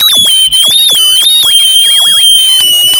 描述：由MuteSynth产生的假短波无线电干扰
Tag: 短波 静音合成器 假短波 干扰 无线